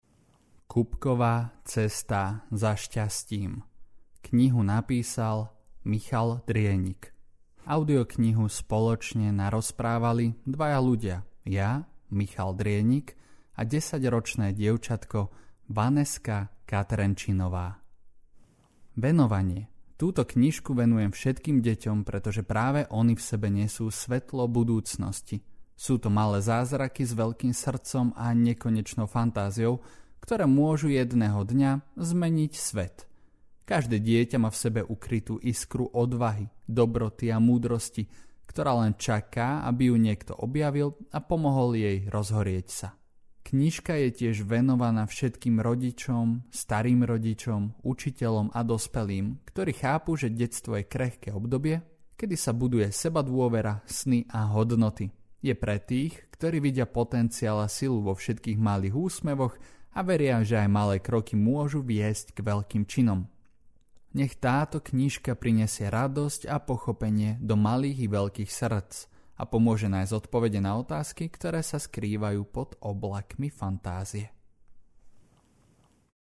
Kubkova cesta za šťastím audiokniha
Ukázka z knihy